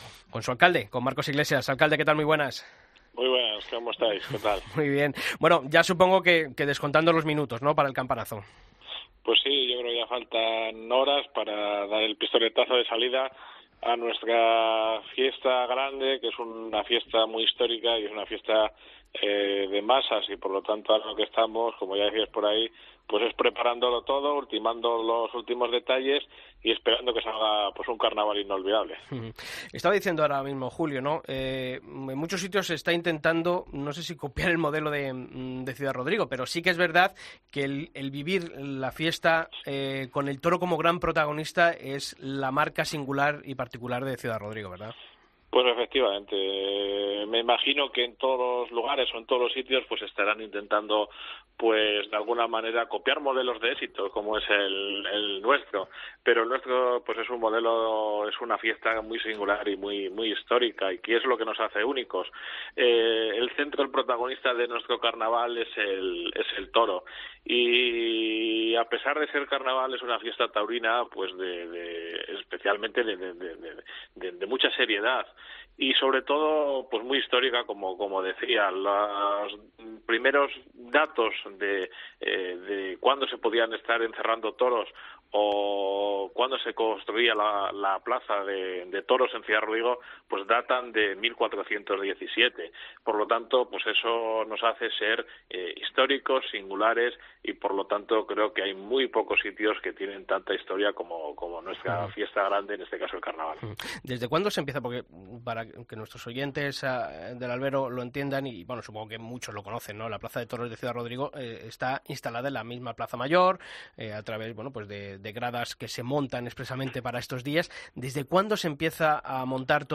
El alcalde de Ciudad Rodrigo habló en El Albero de la importancia del Carnaval del Toro como parte de la cultura de esta localidad salmantina.
Marcos Iglesias, alcalde de Ciudad Rodrigo, en El Albero